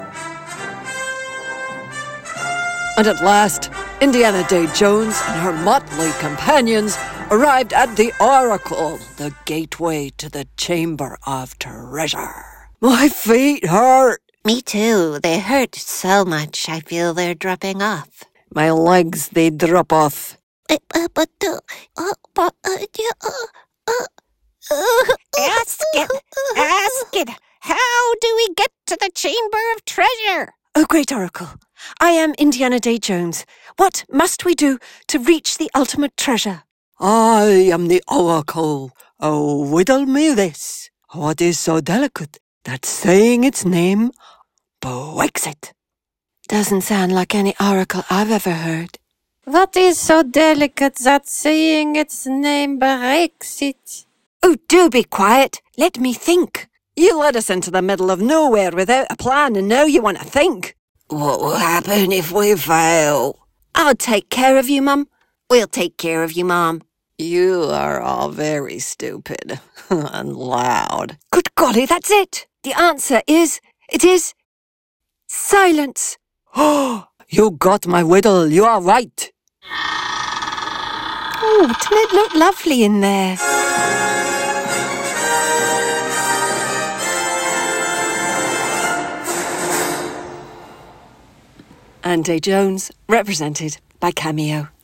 Animation - EN